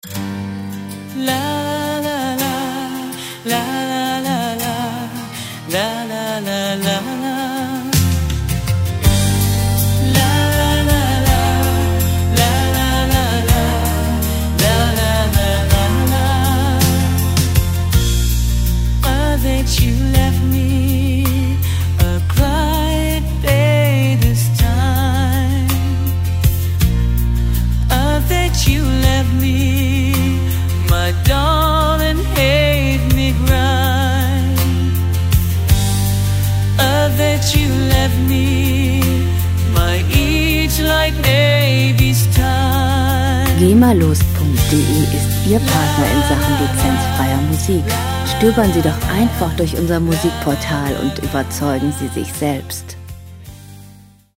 Rockmusik - Naturfreunde
Musikstil: Country Pop
Tempo: 108 bpm
Tonart: G-Moll
Charakter: freundlich, hoffnungsvoll